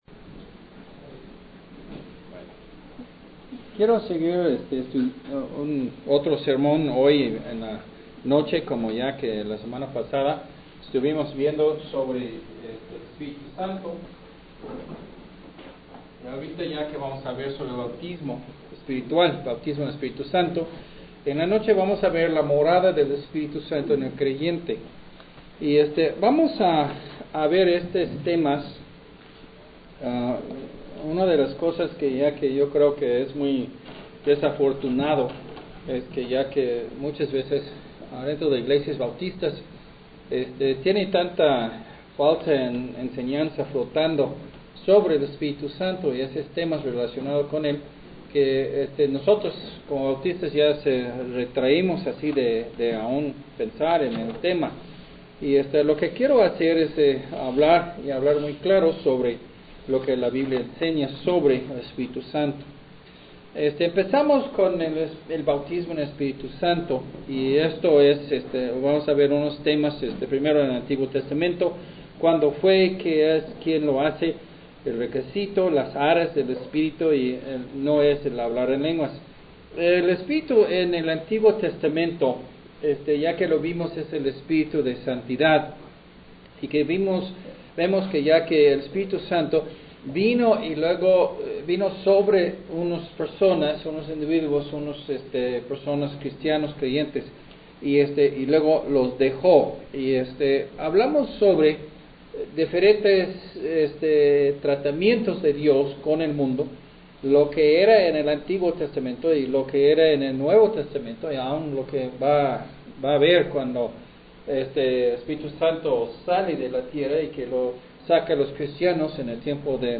Sermón en Audio